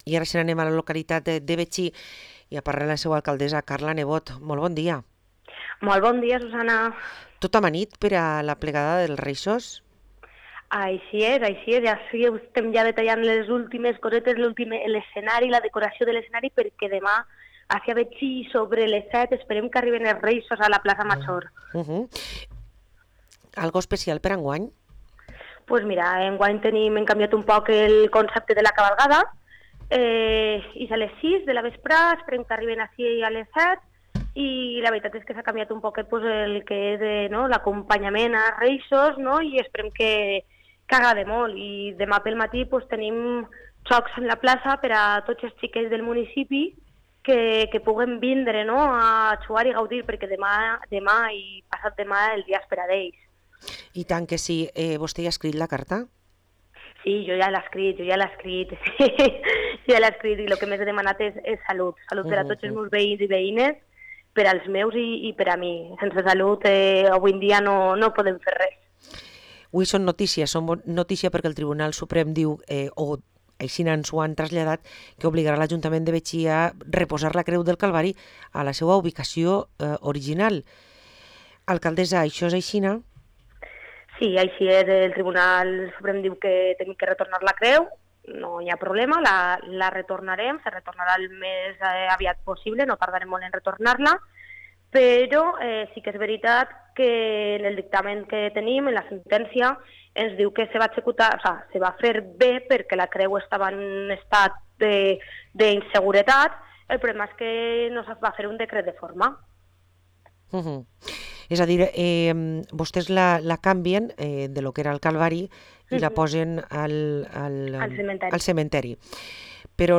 Parlem amb l´Alcaldessa de Betxí, Carla Nebot